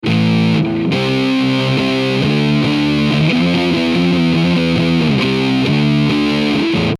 Power Chords are the staple of Rock Music and if you want to play hard driving music this is the type of chord you definitely want to learn.
Iron Man Power Chord Sample